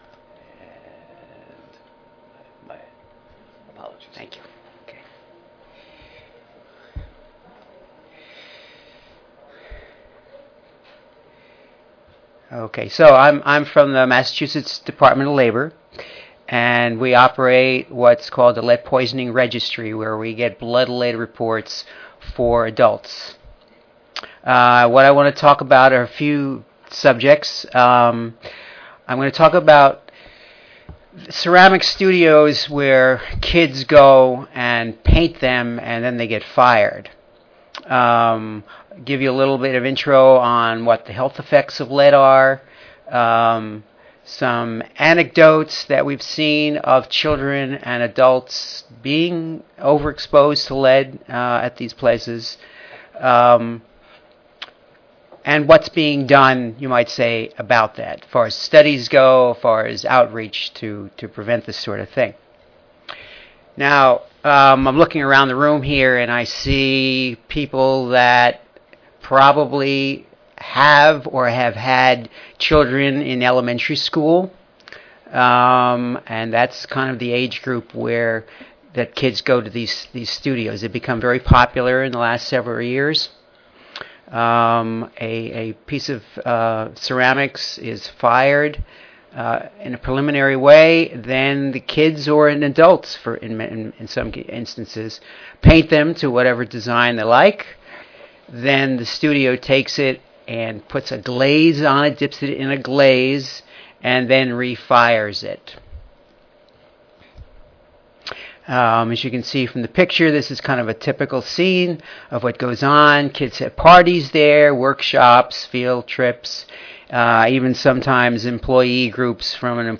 Oral